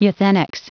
Prononciation du mot euthenics en anglais (fichier audio)
Prononciation du mot : euthenics